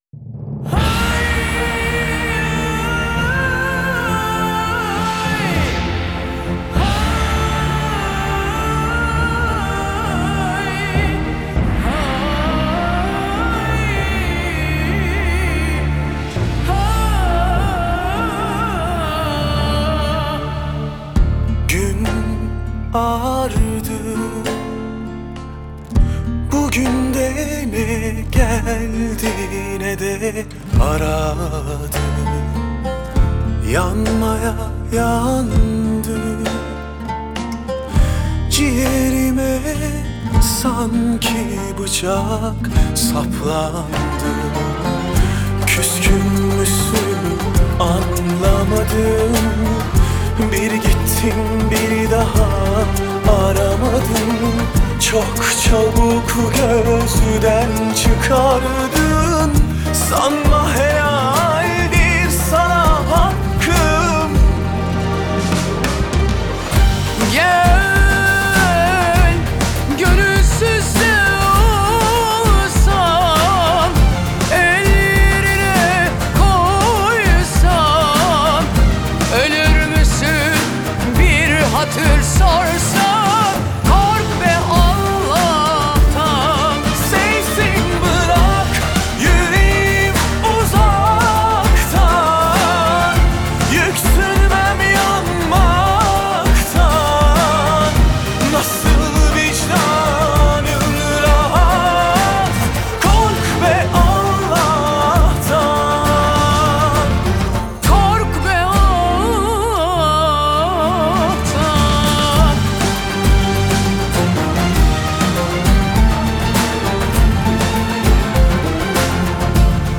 آهنگ ترکیه ای آهنگ غمگین ترکیه ای آهنگ هیت ترکیه ای ریمیکس